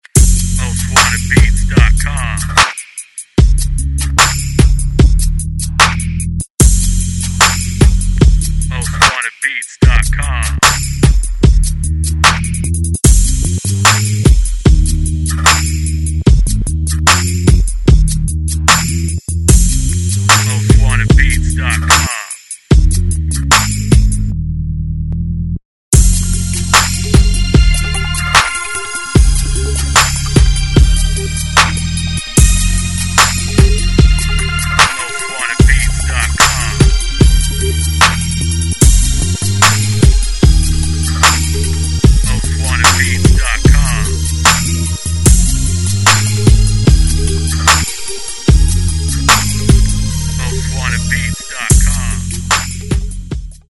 EAST COAST INSTRUMENTAL